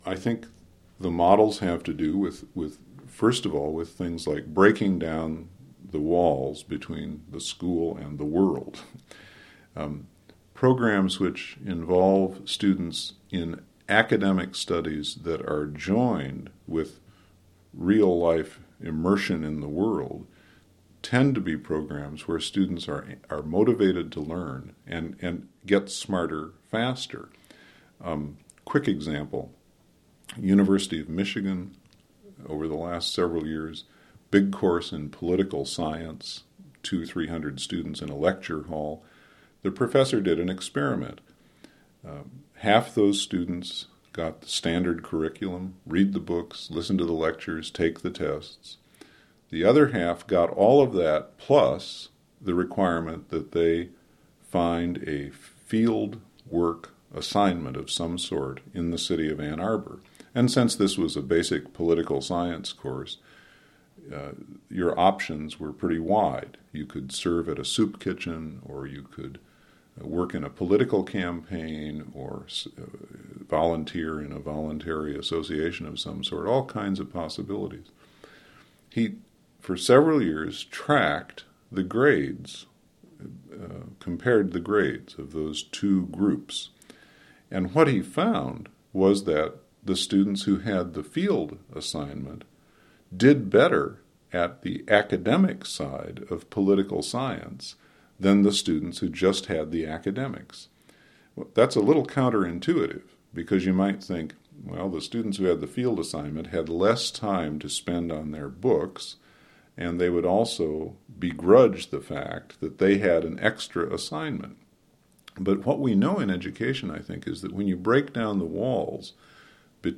In this half-hour of Humankind, we’ll hear from two committed educators: Parker Palmer in Madison, Wisconsin and Nel Noddings in New York City. They discuss what they believe is at the heart of teaching.